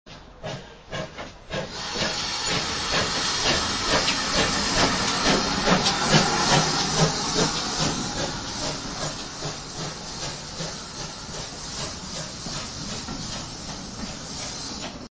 bchuff.mp3